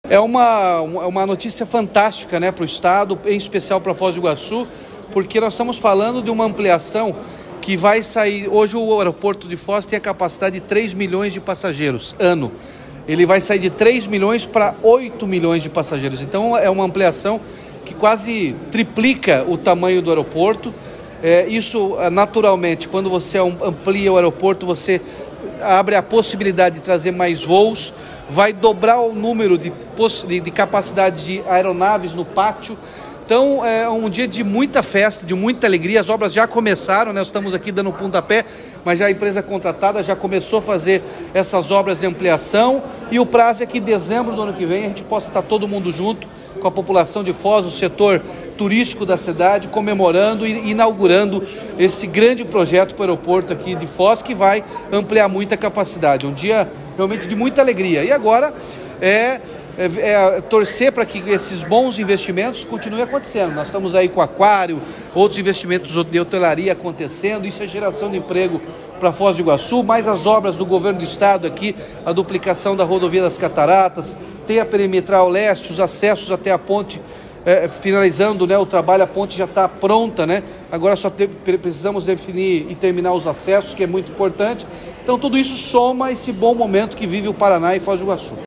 Sonora do governador Ratinho Junior sobre a ampliação do aeroporto de Foz do Iguaçu